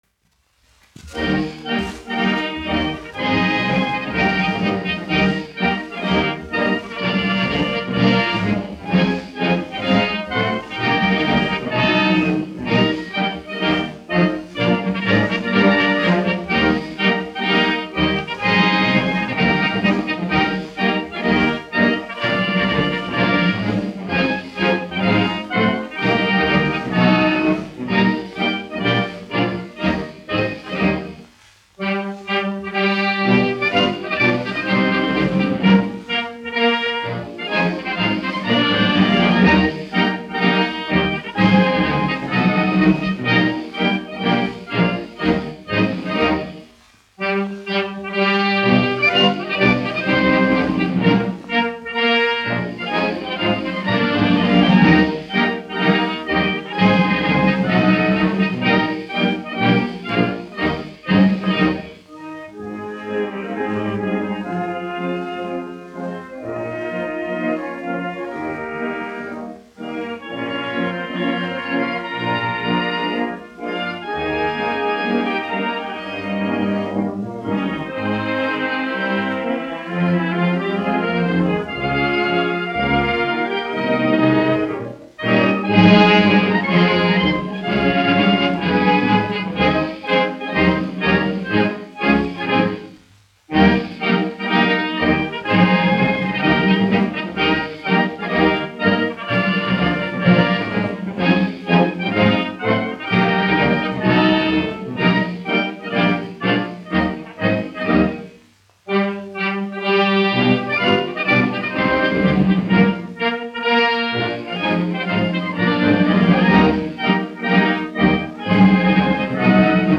1 skpl. : analogs, 78 apgr/min, mono ; 25 cm
Marši
Pūtēju orķestra mūzika
Skaņuplate